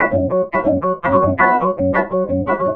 Key-organ-03.wav